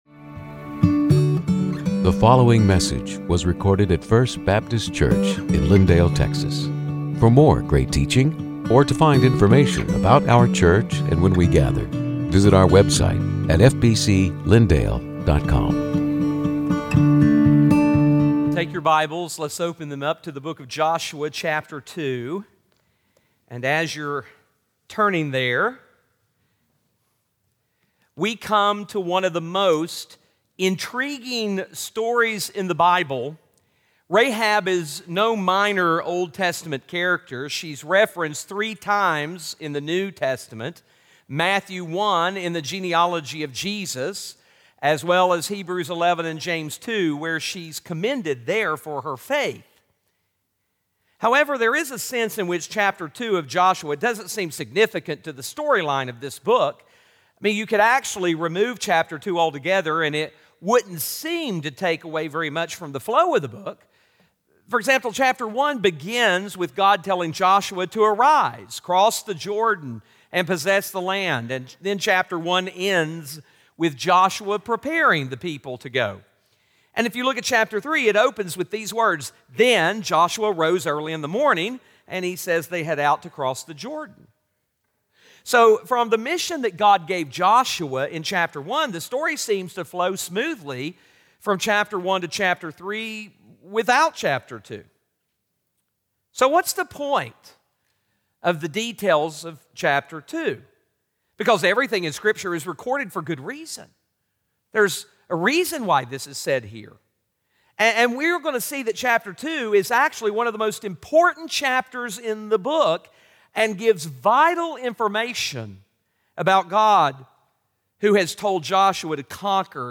Sermons › Joshua 2:1-24